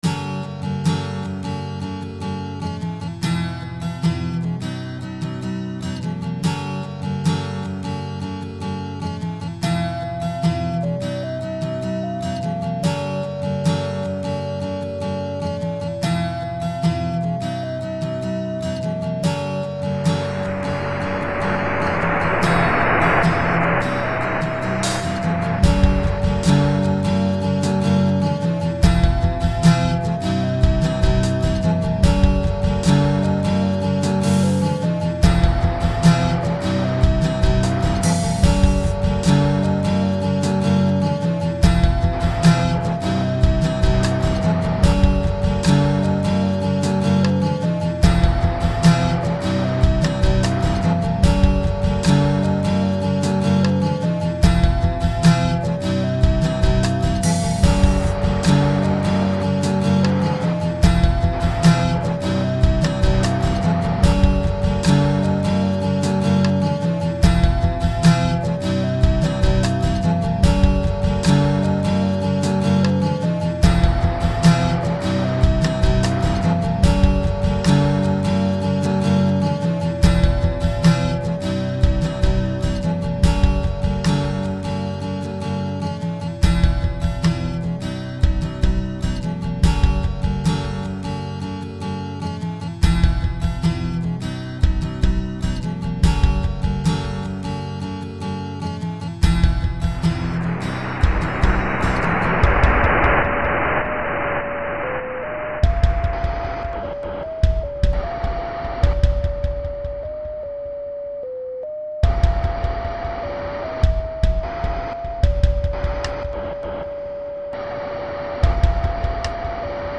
Langsames und entspanntes Stückchen Gitarrenmusik.